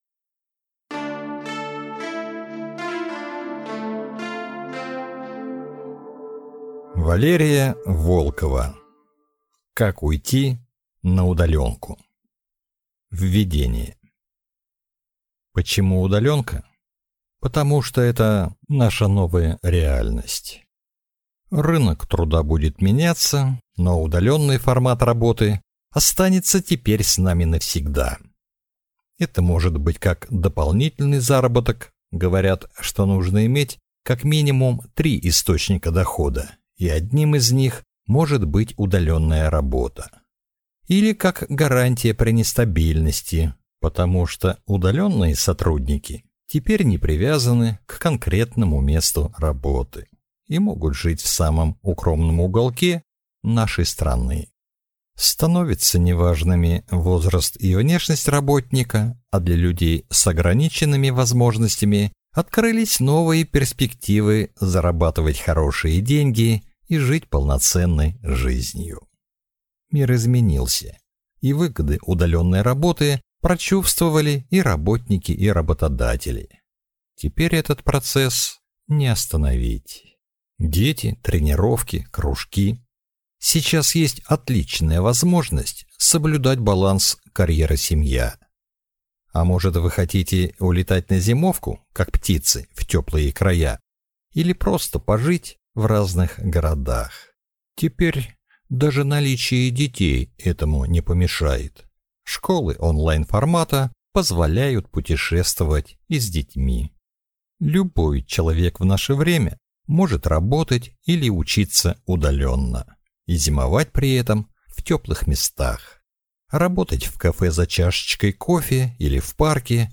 Аудиокнига Как уйти на удалёнку | Библиотека аудиокниг